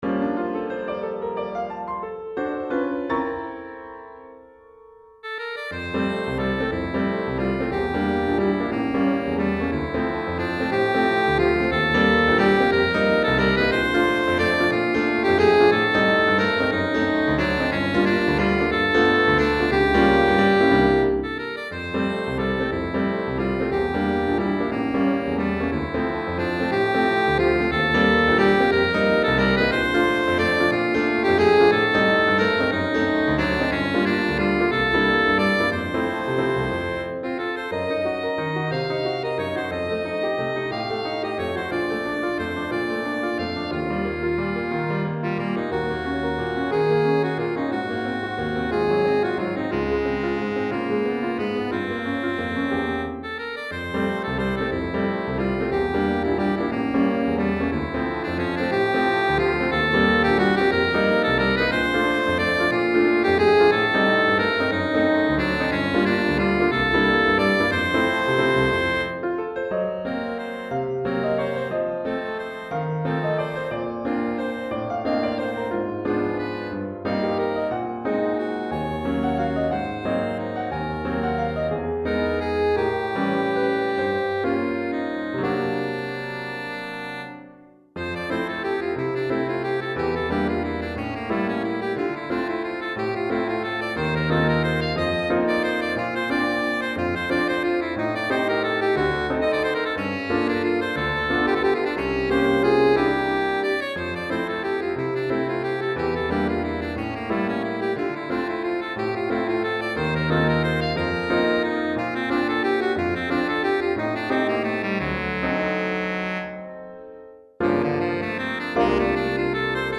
Saxophone et Piano